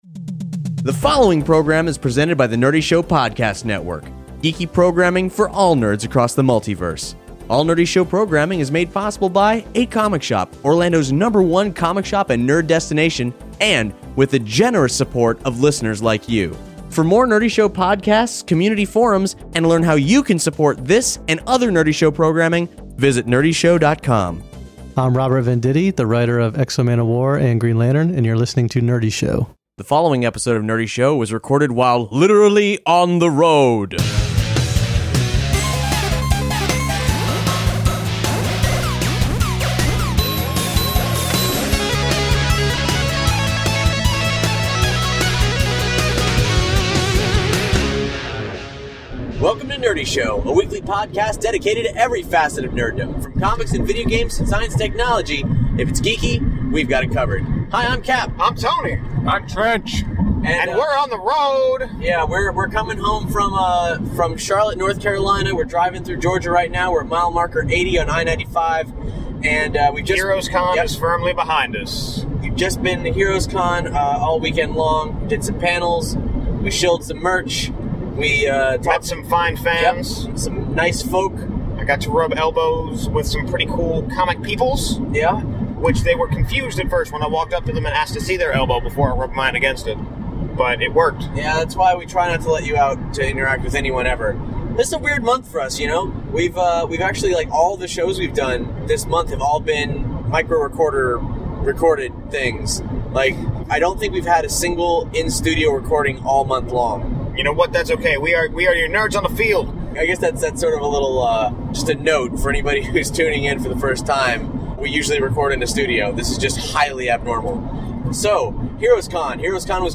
We hung out with some cool folks, met some fine people, moderated some cool panels, and now we’re driving home in the middle of the night.